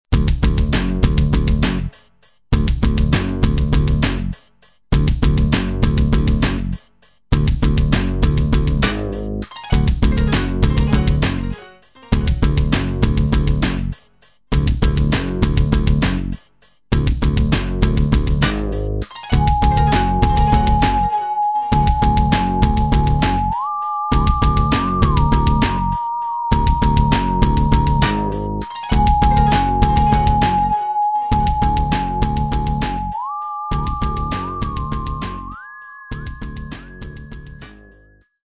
ではここで「火星」面のＢＧＭの原形となった曲をどうぞ。